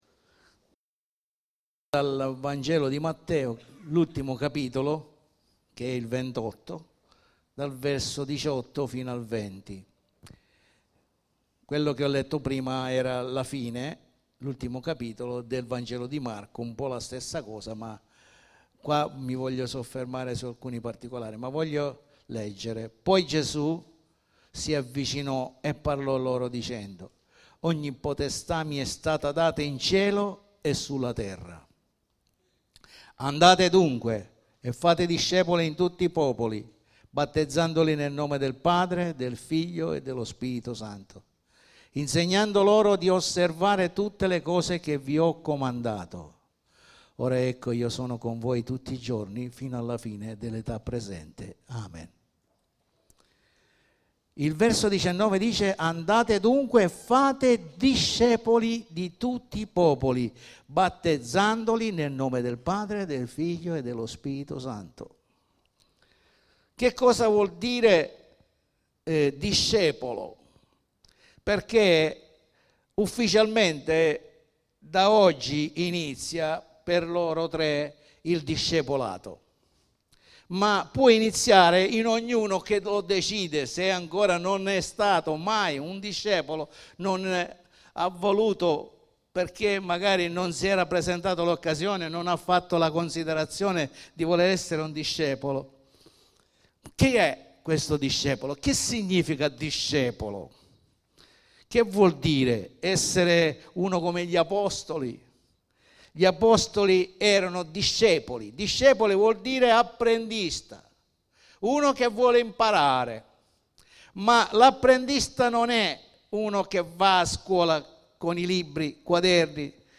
Il vero discepolo (culto di battesimi)
Sezione del sito per l'ascolto dei messaggi predicati la domenica e per il riascolto di studi biblici